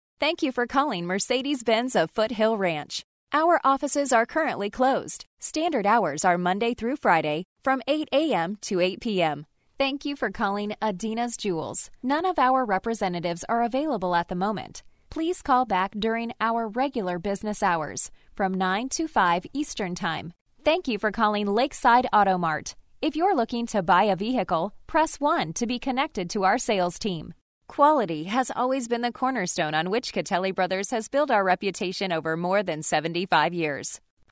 Female
My voice is youthful, upbeat, conversational and relatable.
Phone Greetings / On Hold
On Hold/Telephany